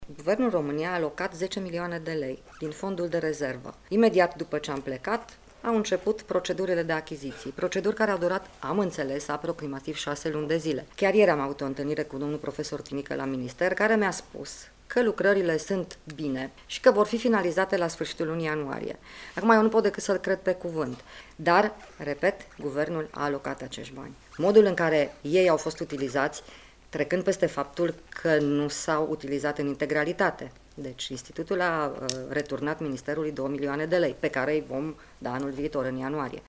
Ministrul Sănătăţii a dat asigurări că ultimele două milioane vor fi realocate pe fila de buget a anului viitor pentru efectuarea reparaţiilor: